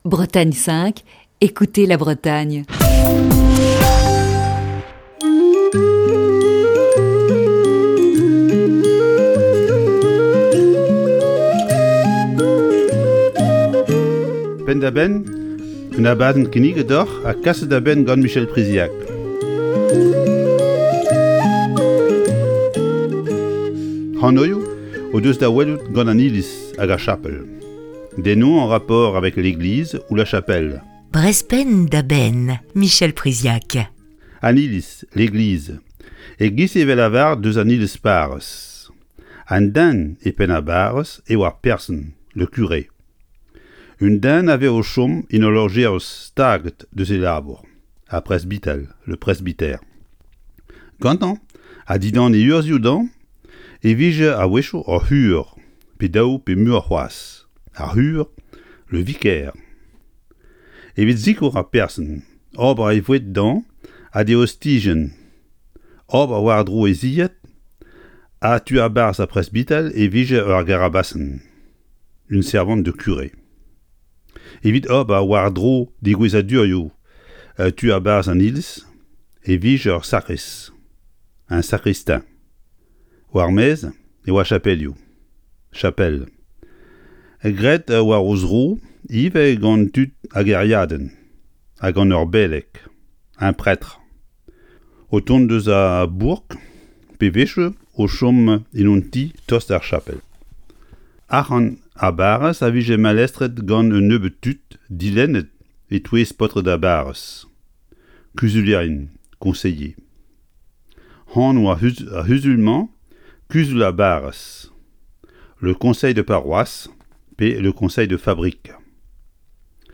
C'est jeudi, c'est cours de breton dans Breizh Penn da Benn.